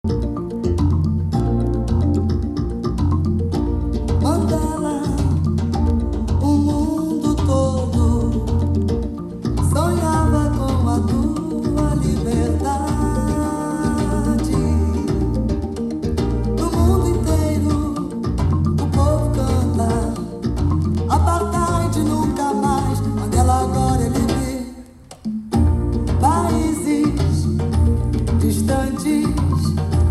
Gênero: Funk, Latin, Soul
com a reprodução original do disco anunciado